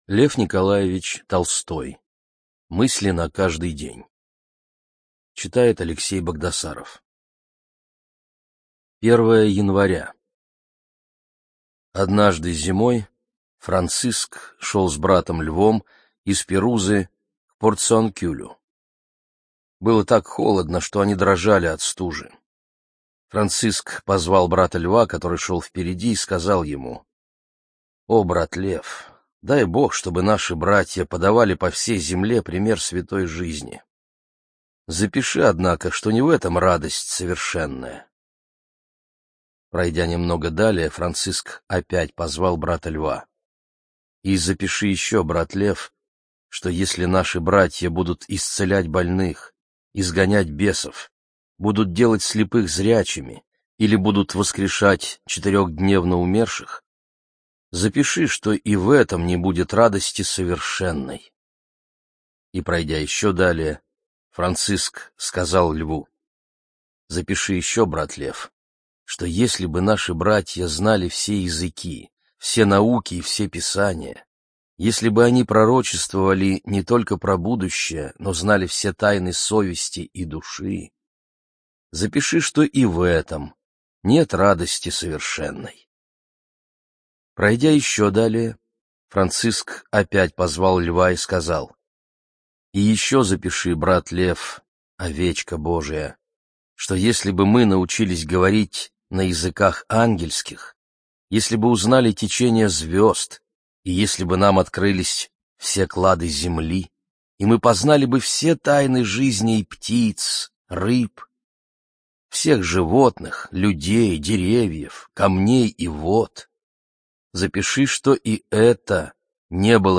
ЖанрАфоризмы